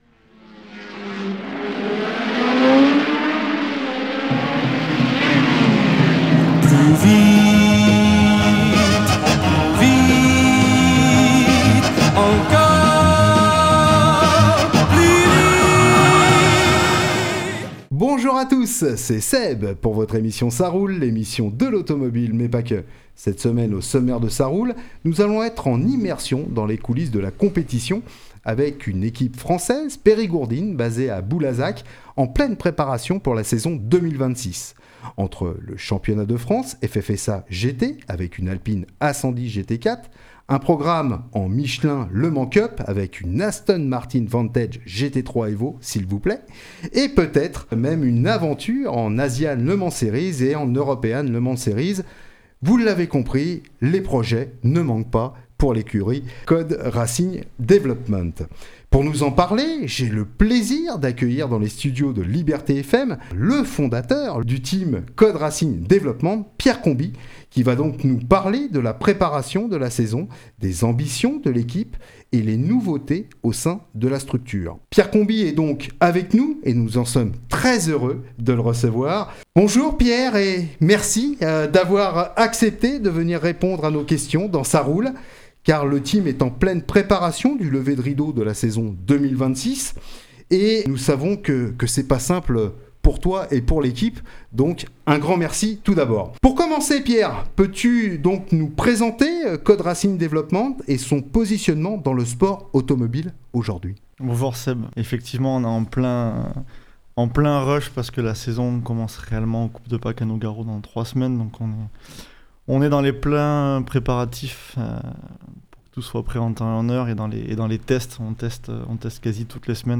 dans les studios de Liberté FM